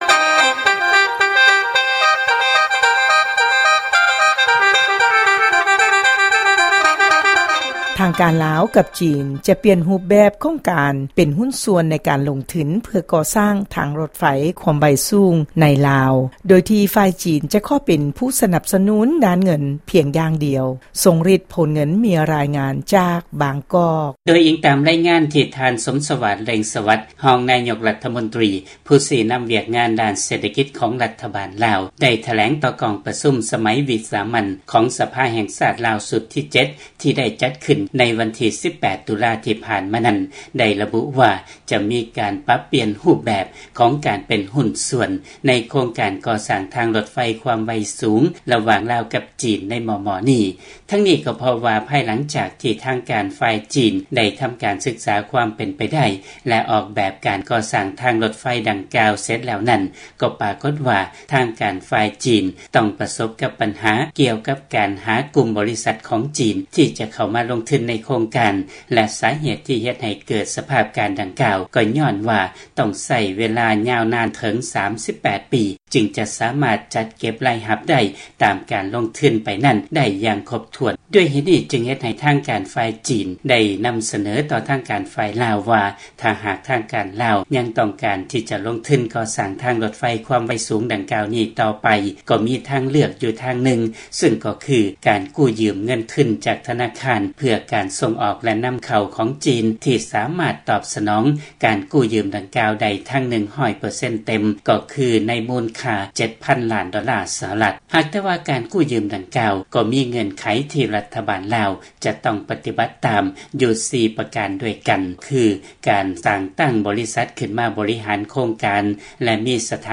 ຟັງລາຍງານກ່ຽວກັບລາວແລະຈີນປ່ຽນຮູບແບບຮຸ້ນ